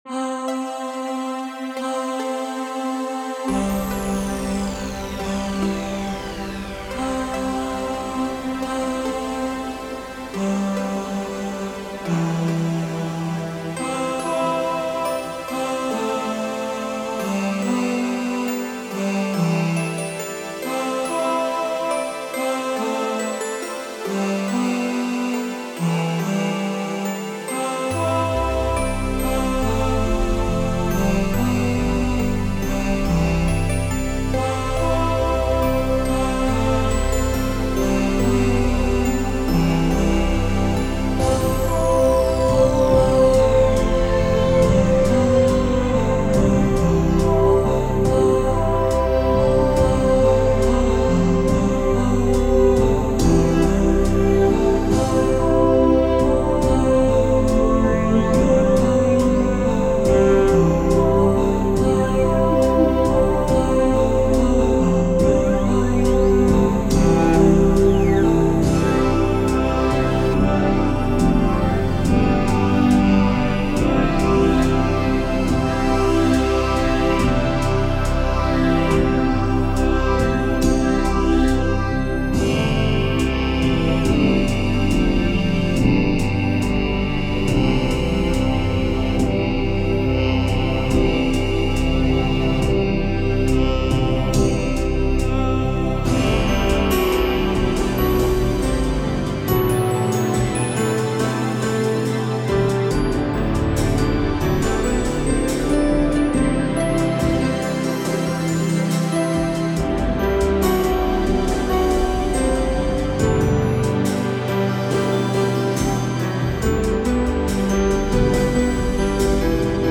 Chillige electronische Sounds aus dem Allgäu.